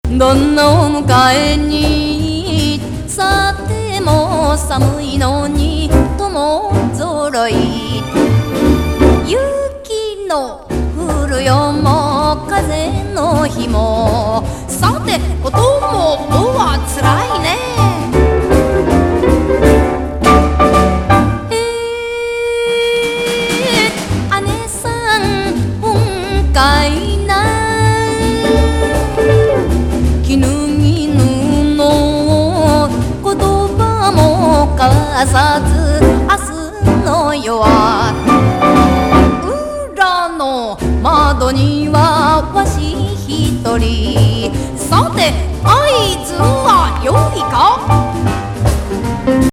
国産スウィンギン・ジャイブ!